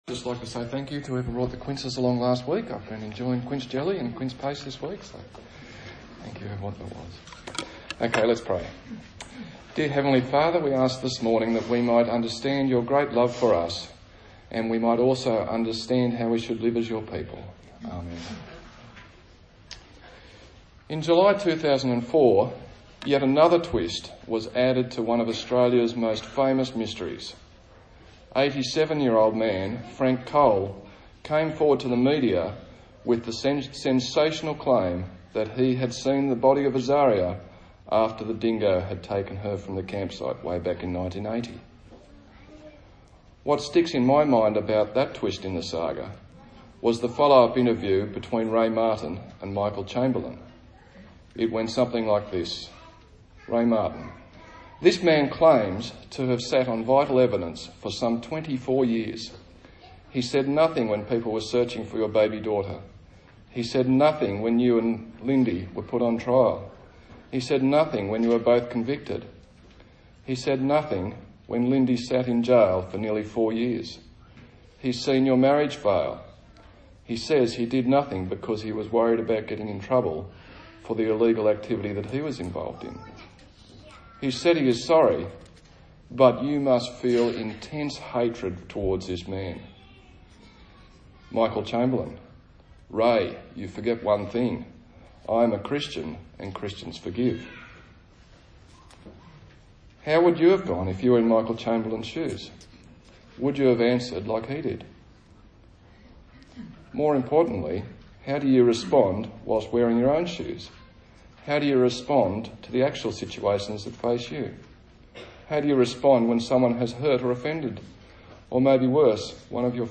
Matthew Passage: Matthew 6:5-15 Service Type: Sunday Morning